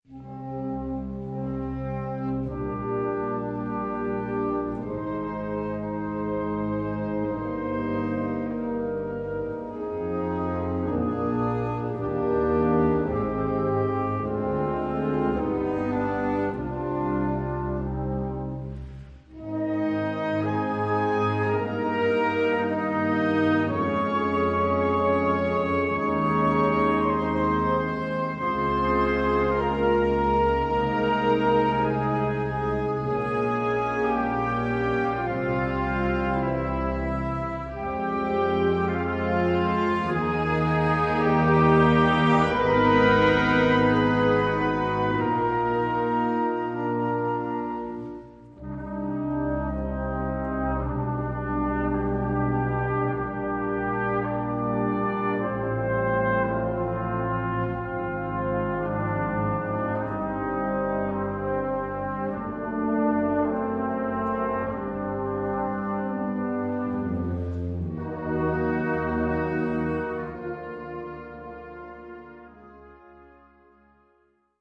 Gattung: Choral
Besetzung: Blasorchester